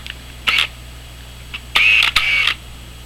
就好像是生锈的齿轮很久没有上润滑油似的。
这是7 用80-200mm f2.8的对焦声音。